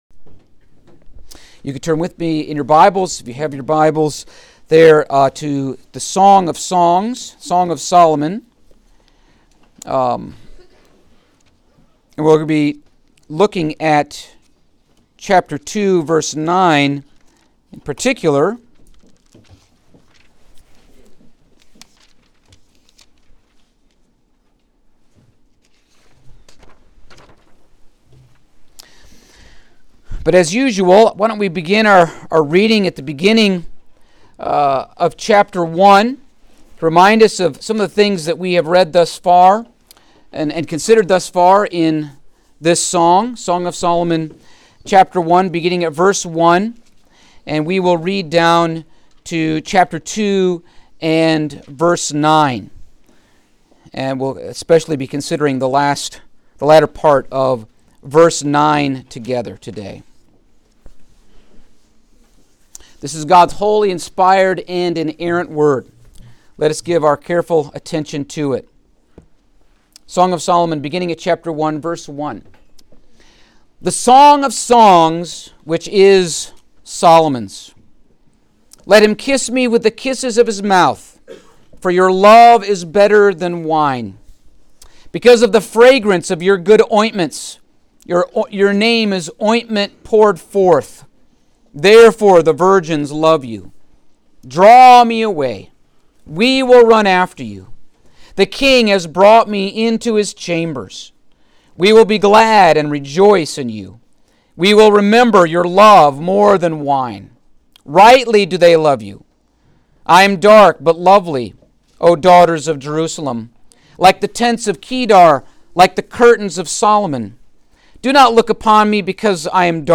Passage: Song of Solomon 2:9 Service Type: Sunday Morning